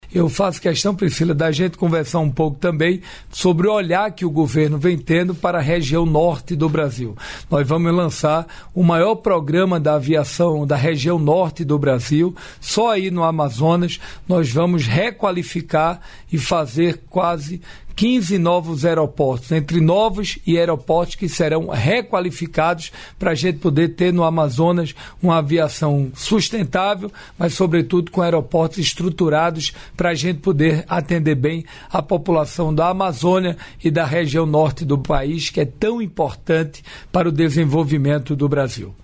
Trecho da participação do ministro de Portos e Aeroportos, Silvio Costa Filho, no programa "Bom Dia, Ministro" desta quinta-feira (06), nos estúdios da EBC, em Brasília.